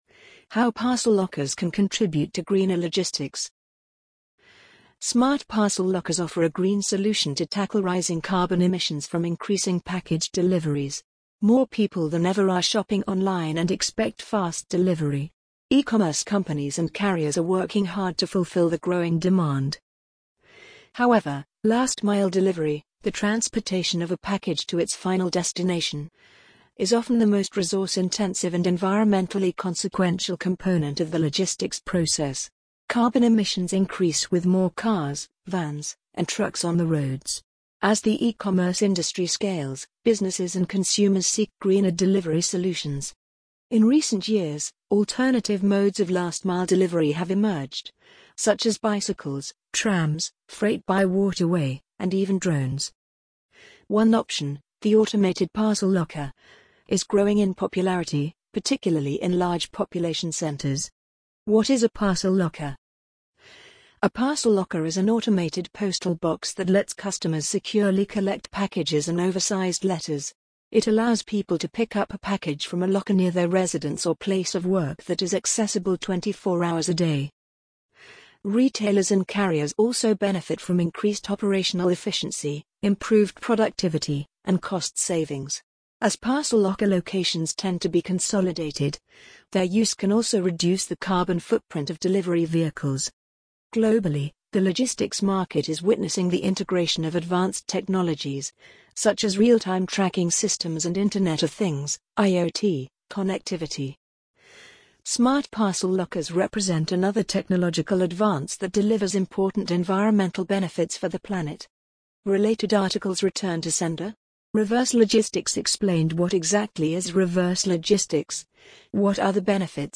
amazon_polly_44890.mp3